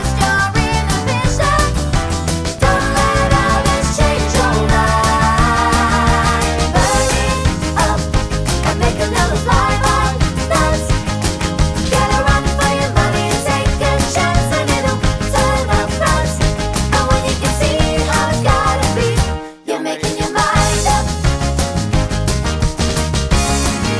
No Guitars Pop (1980s) 2:38 Buy £1.50